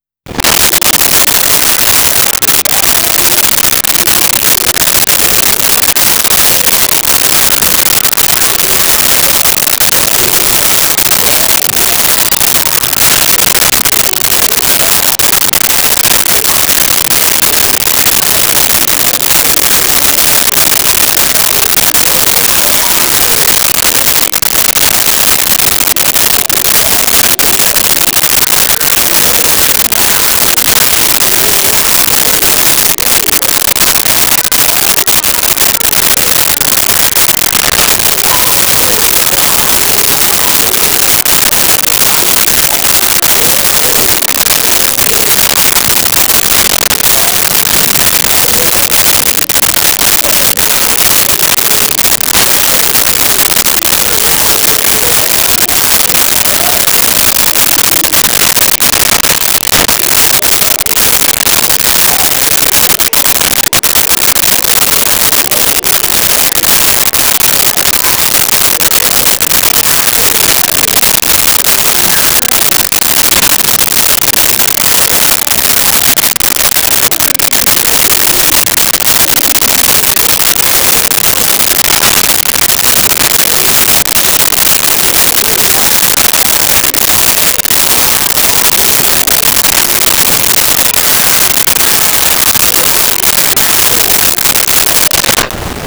Laughing Playing Children
Laughing Playing Children.wav